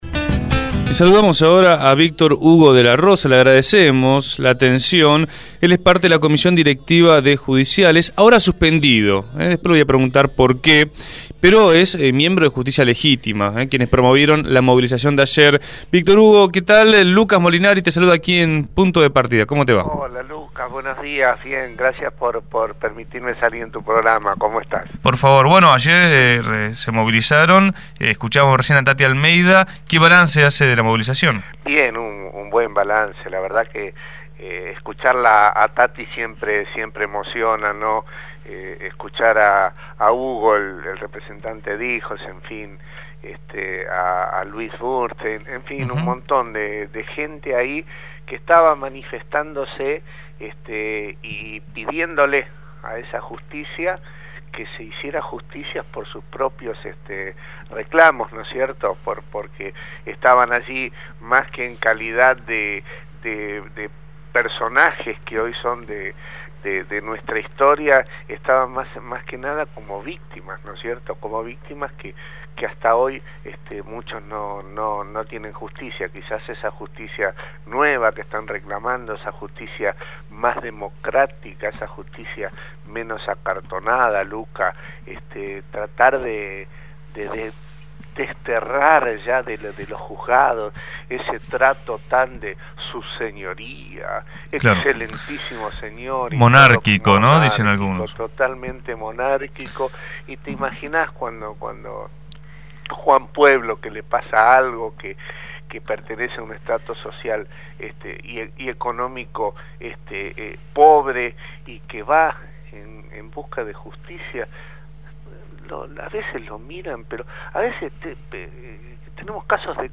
Entrevistado en Punto de Partida